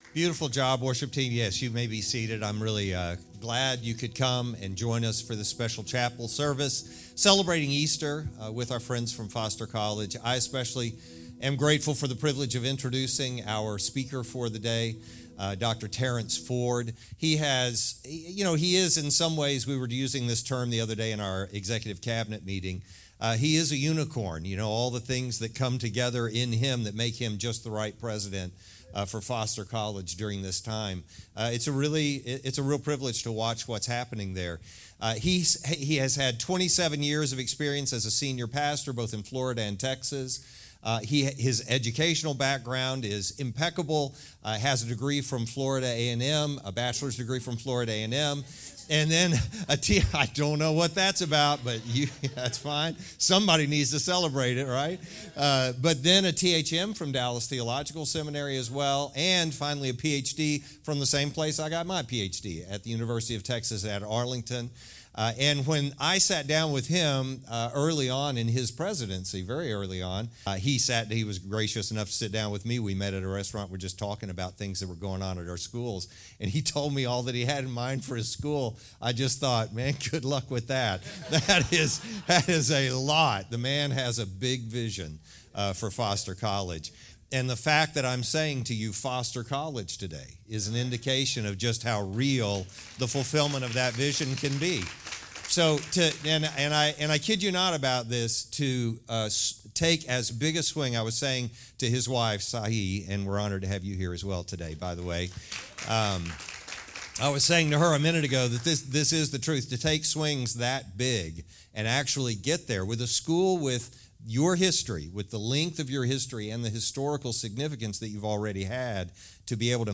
Criswell College Easter Chapel.